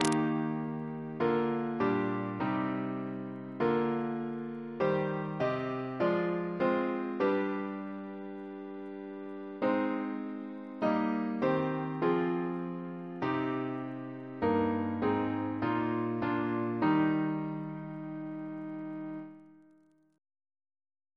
Double chant in E♭ Composer: Kellow J. Pye (1812-1901) Reference psalters: ACB: 298; ACP: 150; CWP: 73; OCB: 107; PP/SNCB: 174; RSCM: 111